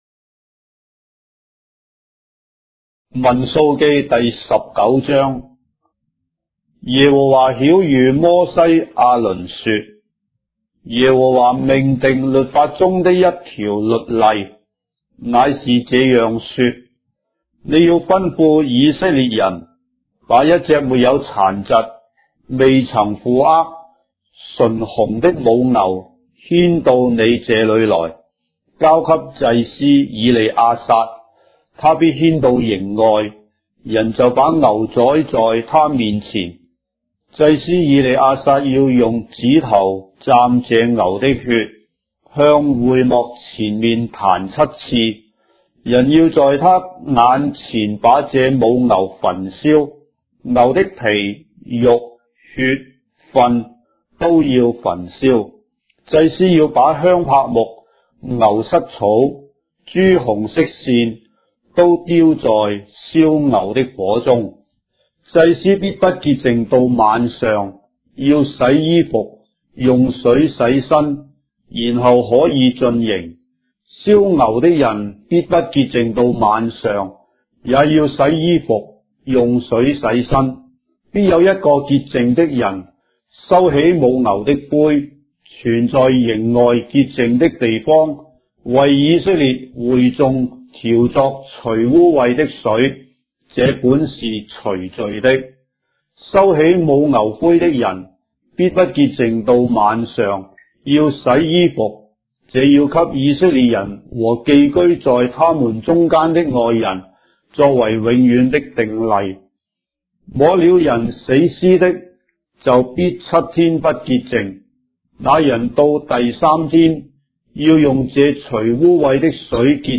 章的聖經在中國的語言，音頻旁白- Numbers, chapter 19 of the Holy Bible in Traditional Chinese